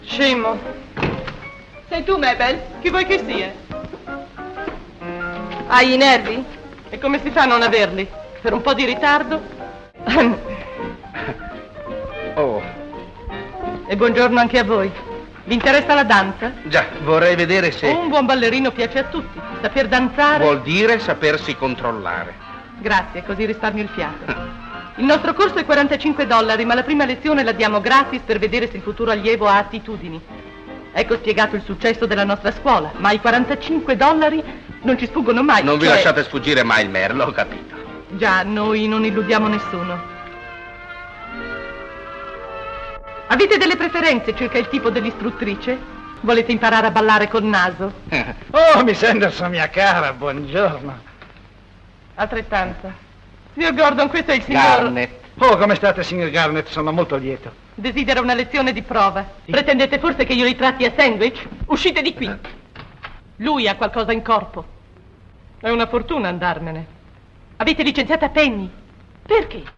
voce di Margherita Bagni nel film "Follie d'inverno", in cui doppia Helen Broderick.